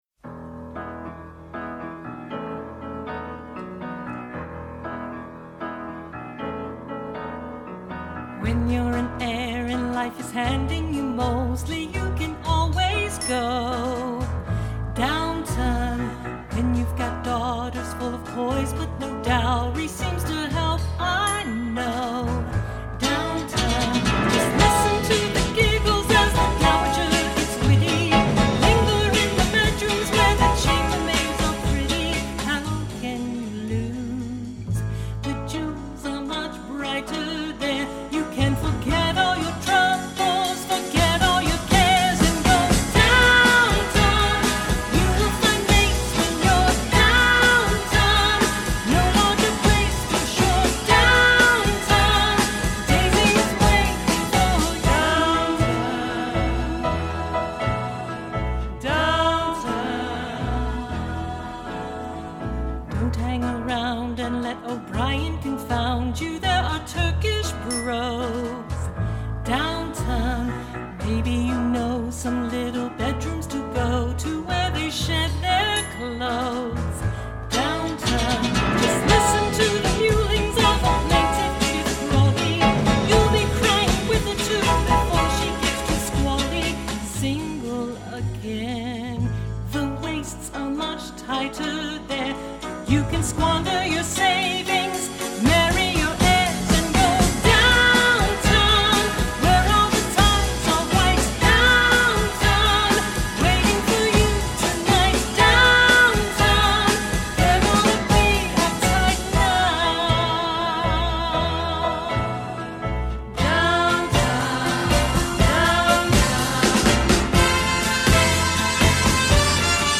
parody